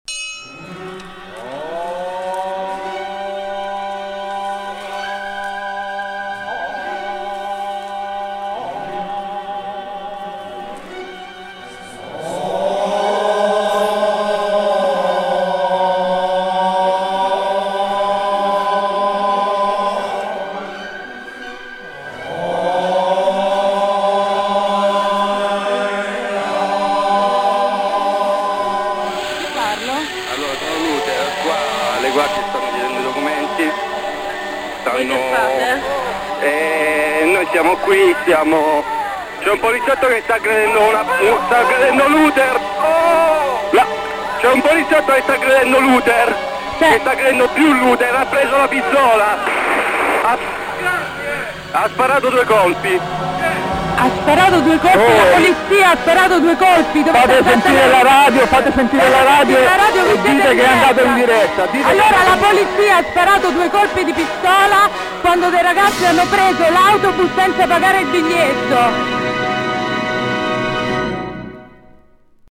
En el verano de 1995, una retransmisión de madrugada del programa Blissett Radio en Radio Città Futura en Roma tuvo como consecuencia una rave espontánea en un autobús, que tuvo que ser dispersada por la policía, no sin la presencia de violencia y arrestos.
Cuarenta personas se suben a un autobús nocturno con radios, tambores, instrumentos de viento y un teléfono móvil.
El móvil de Luther está operativo y retransmite el disparo a la emisora de radio.